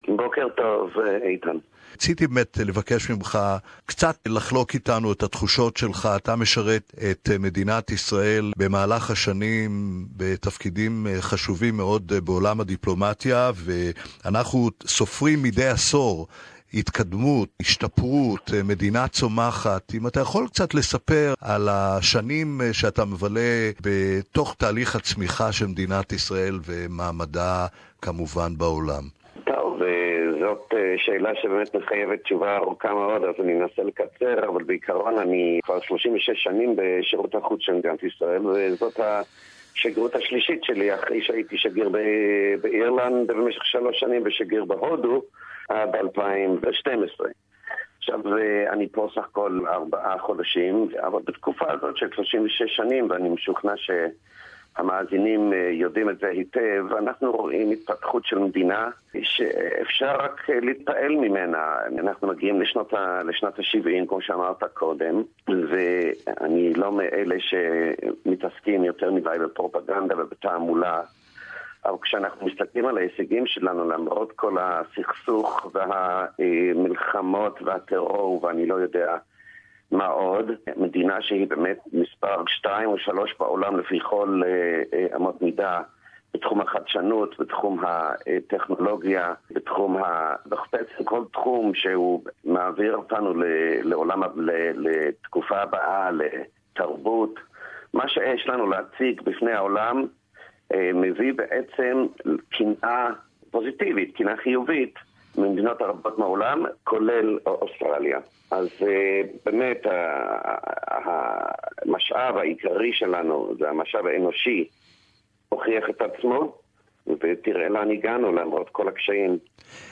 Interview with Ambassador of Israel to Australia Mark Sofer